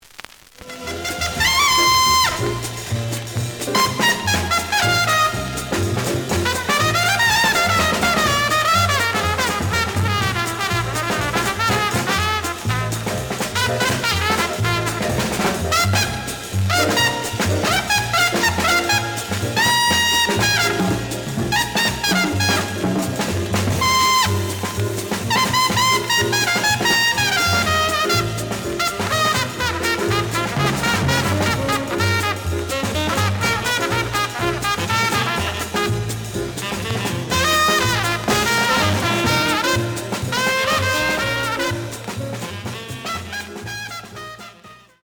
The audio sample is recorded from the actual item.
●Genre: Cool Jazz